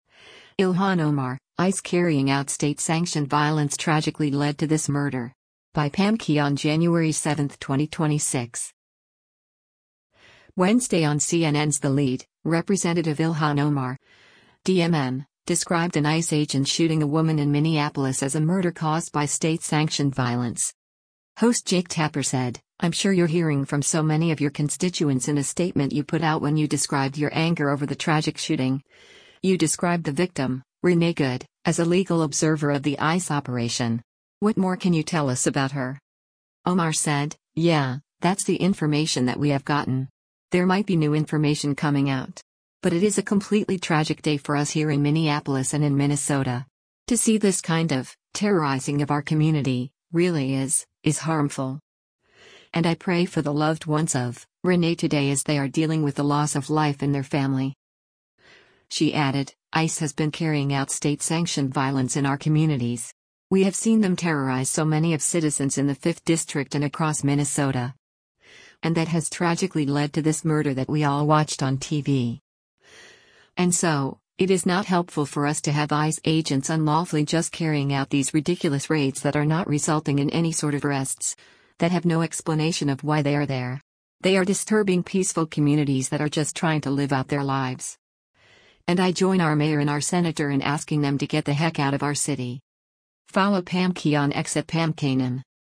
Wednesday on CNN’s “The Lead,” Rep. Ilhan Omar (D-MN) described an ICE agent shooting a woman in Minneapolis as a “murder” caused by “state-sanctioned violence.”